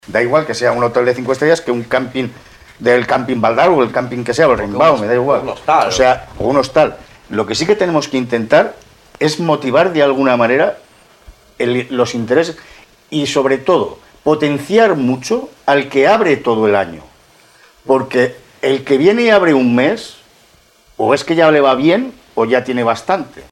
El debat electoral Castell-Platja d’Aro 2019 s’ha emès a Ràdio Capital aquest dimarts al vespre amb la presència de tots els candidats a l’alcaldia.